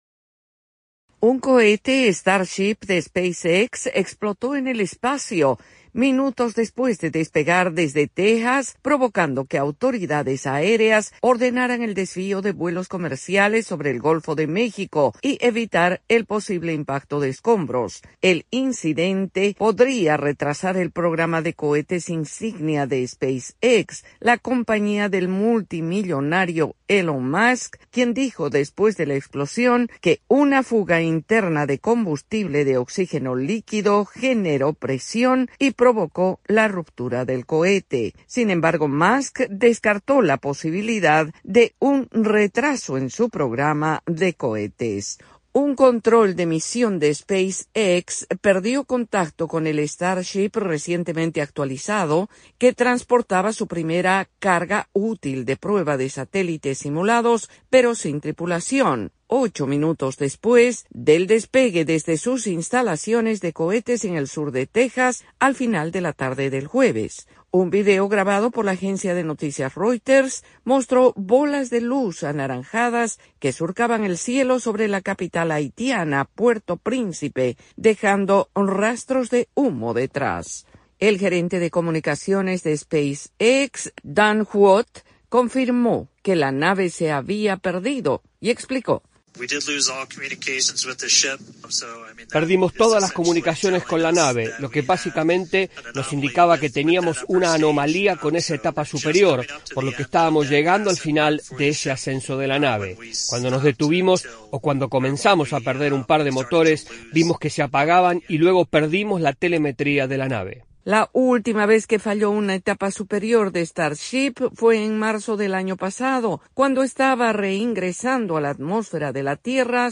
AudioNoticias
desde la Voz de América en Washington DC.